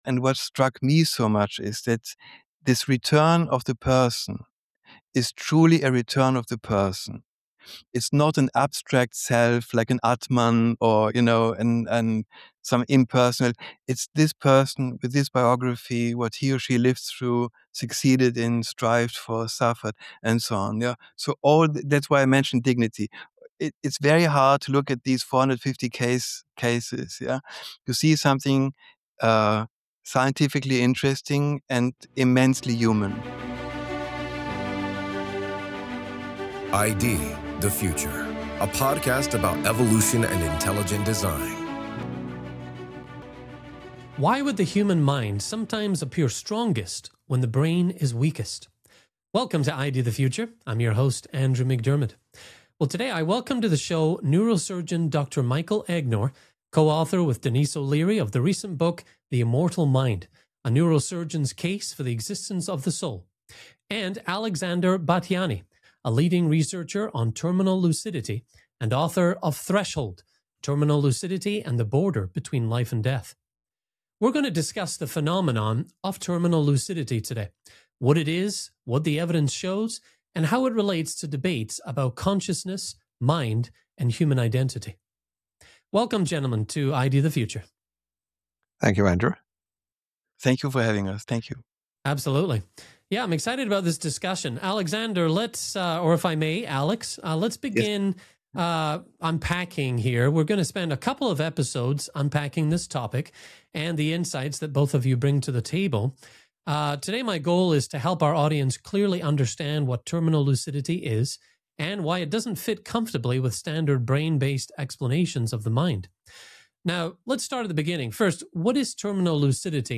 The trio begins a two-part conversation discussing the phenomenon of terminal lucidity: what it is, what the evidence shows, and how it relates to debates about consciousness, mind, and human identity.